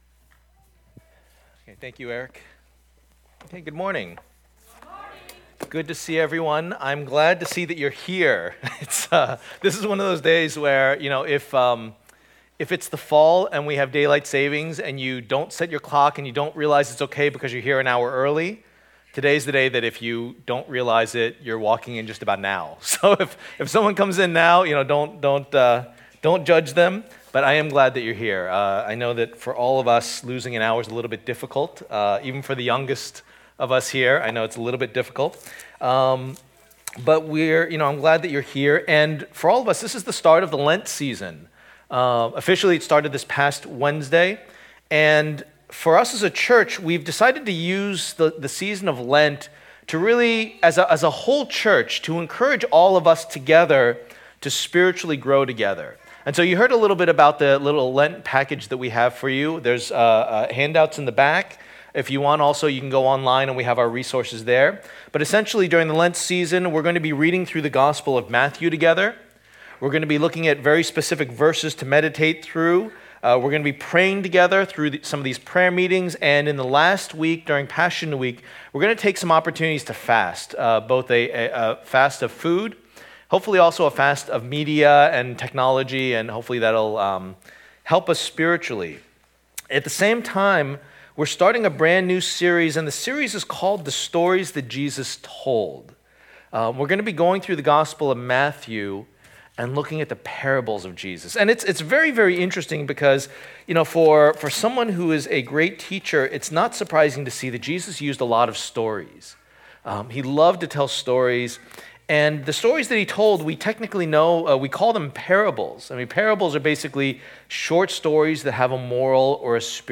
2019 The Ears to Hear Preacher